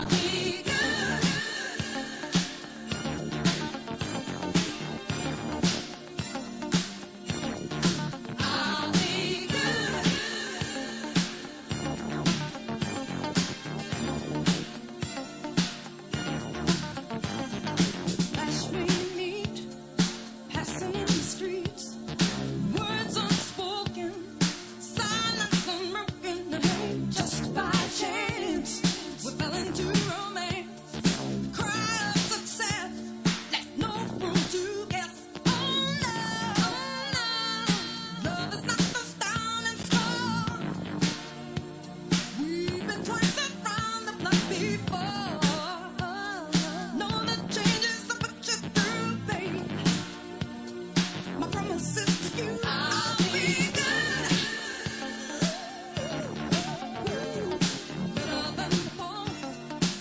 Absolute 80’s bombs